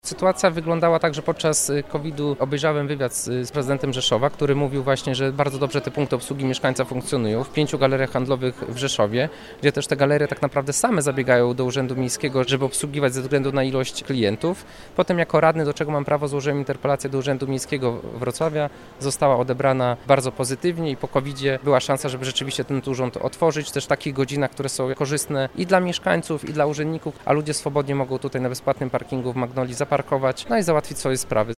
Mówi Dariusz Piwoński, klub radnych Prawa i Sprawiedliwości.